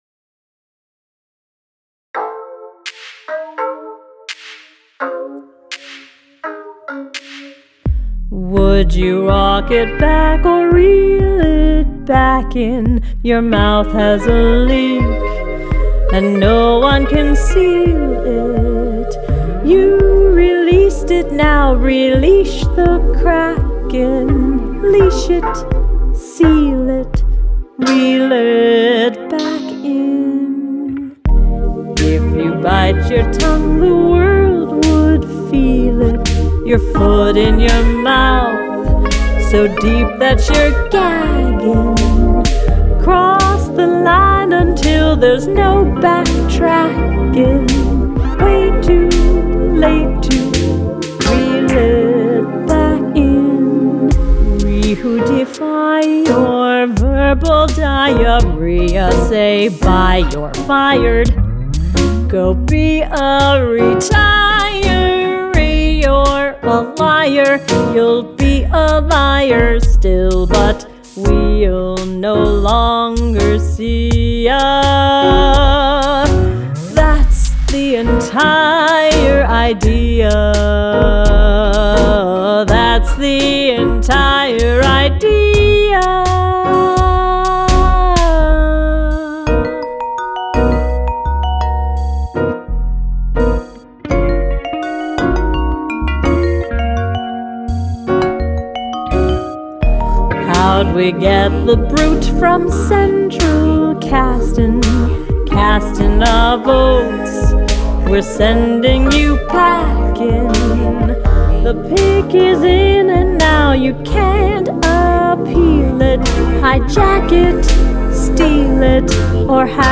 vocals